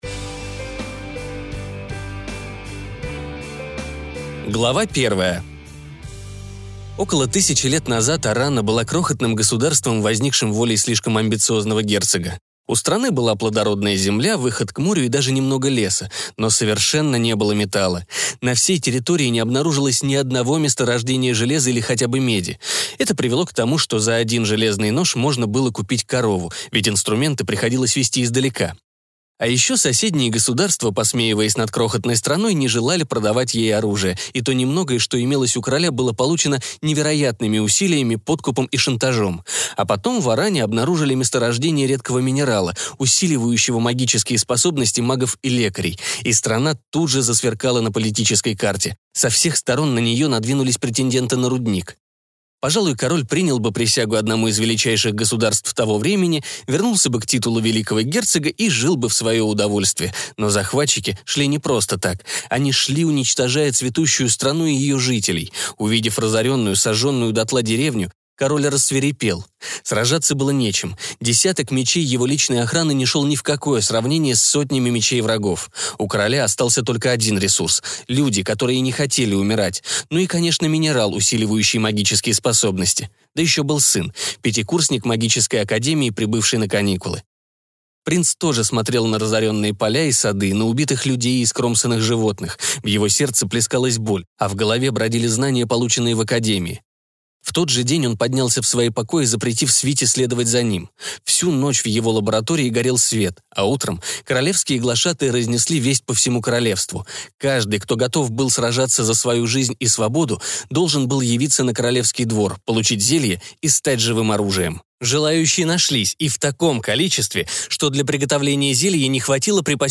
Аудиокнига Живые клинки | Библиотека аудиокниг